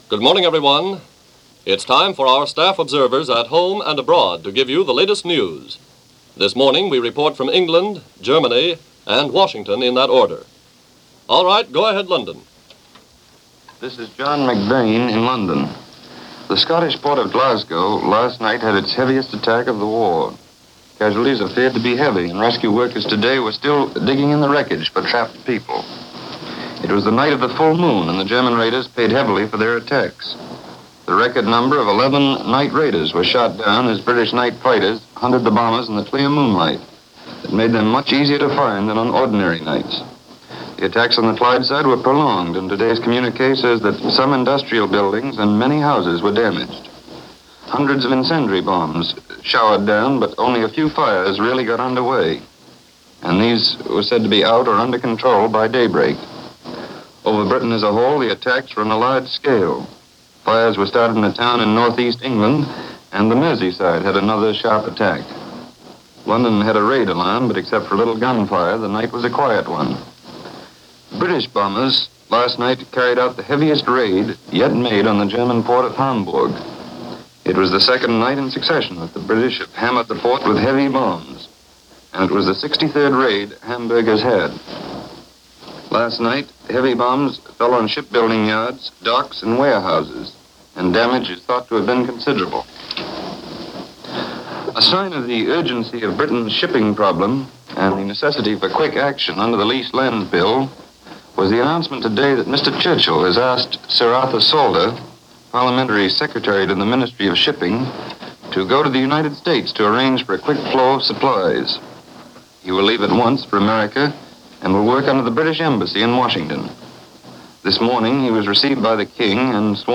News Of The World – March 14, 1941 – NBC – Gordon Skene Sound Collection –